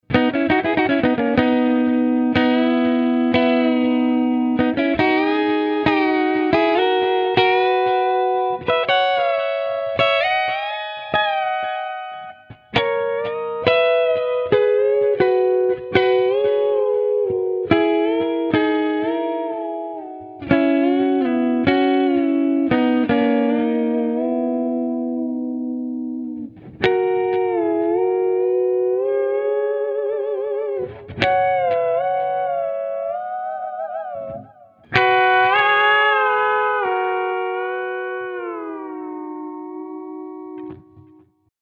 Controlling a voice harmony value in pitch block's intel. harm. mode allows smooth bending between scale notes for pedal-steel type effects.
These presets just have one voice set up like in the sound clips, Ext Ctrl 3 bending from +3rd to +4th in B major.
diatonic-bender.mp3